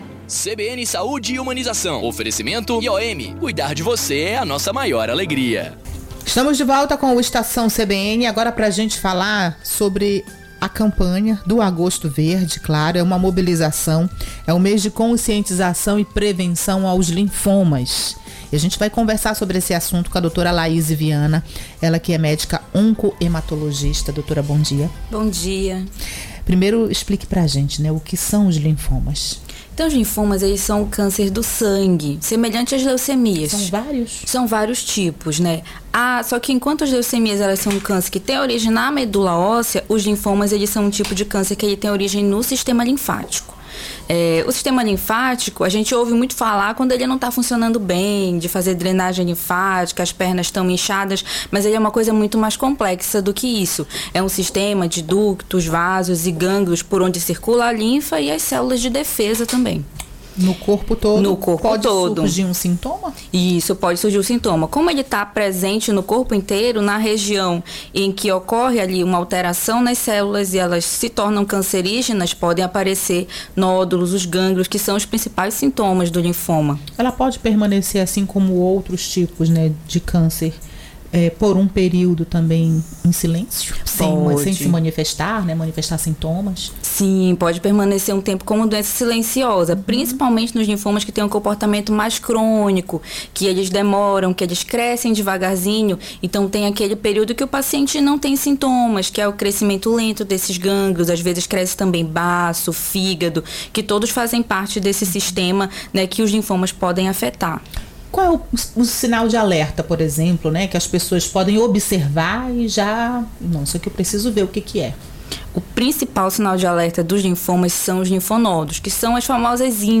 entrevista-cbn-sade-e-humanizao.mp3